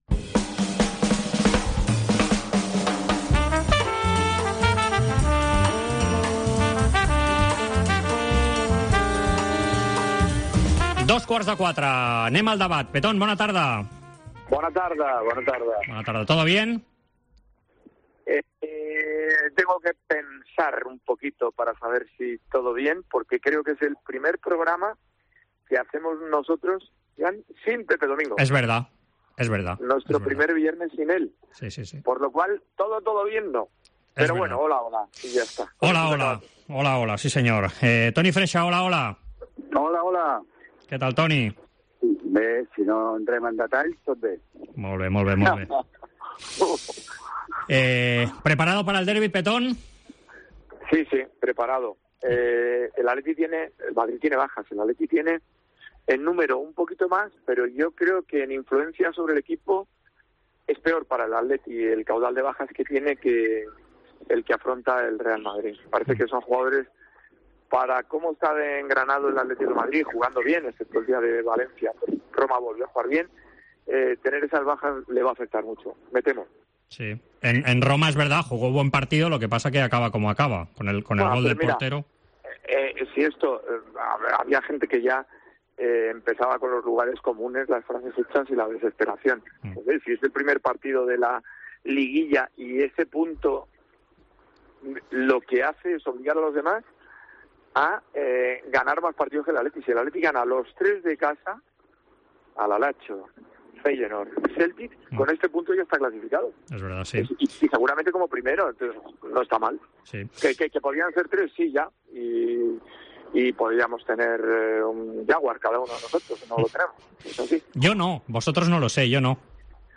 AUDIO: Els dos col·laboradors de la Cadena COPE repassen l'actualitat esportiva d'aquesta setmana.